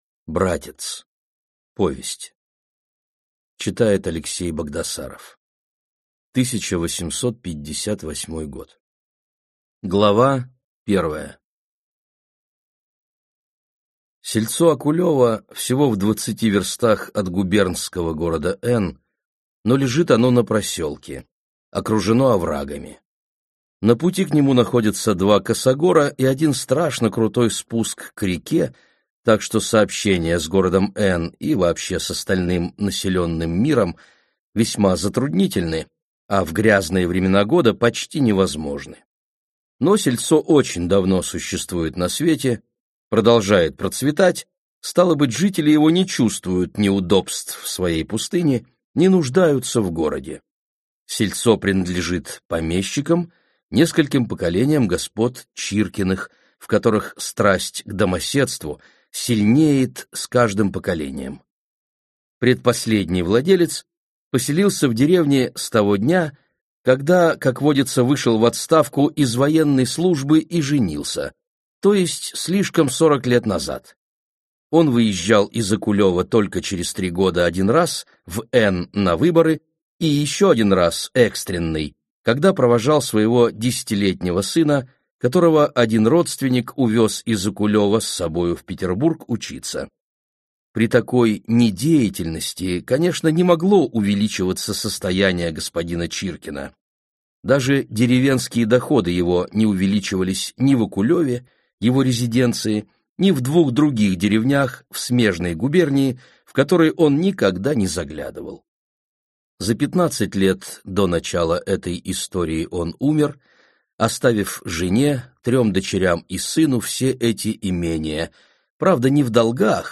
Аудиокнига Братец | Библиотека аудиокниг
Читает аудиокнигу